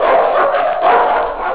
Dogs
DOGS.wav